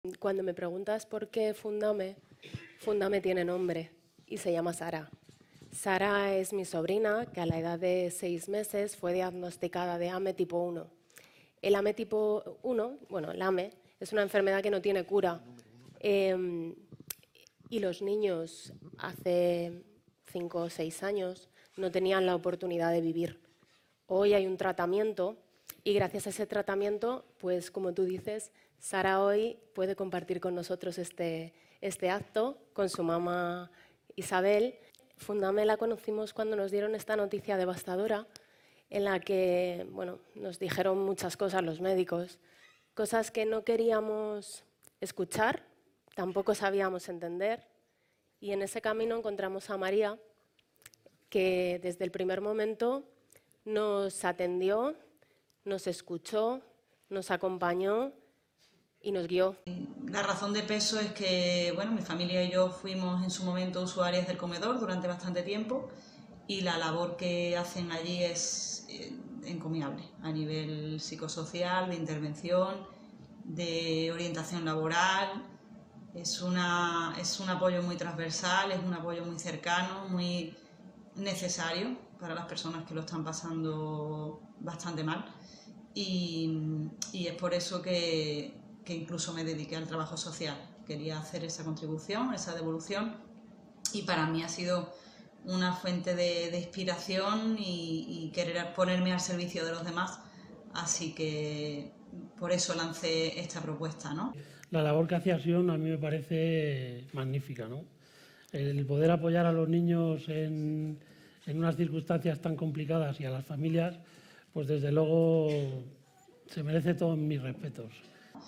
El acto de entrega de los cheques solidarios del programa ‘Gracias A Ti’ reunió a trabajadores de todas las áreas ejecutivas del Grupo Social ONCE (ONCE, Fundación ONCE e ILUNION) en un acto celebrado en el Palacete de los Duques de Pastrana en Madrid el pasado 10 de febrero, que también pudo seguirse en streaming.
TestimoniosEmbajadores.mp3